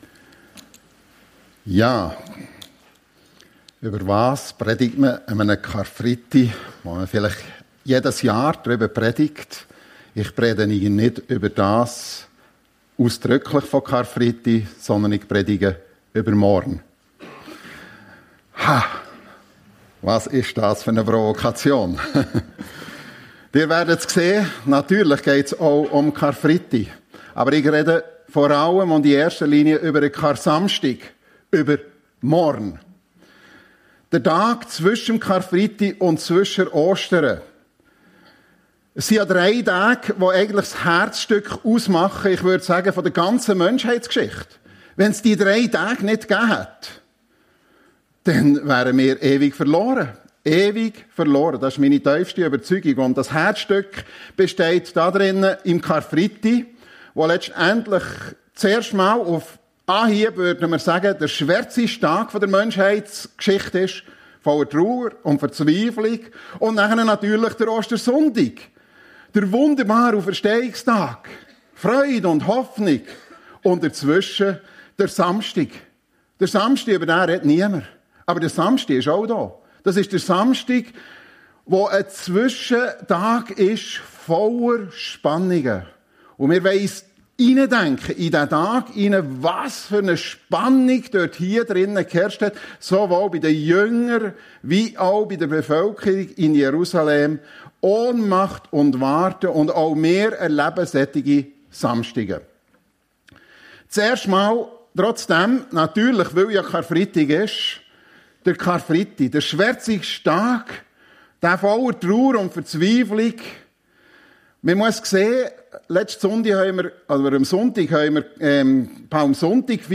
Drei Tage - Das Herzstück der Menschheitsgeschichte Predigt